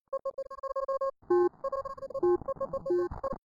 decodephaser.mp3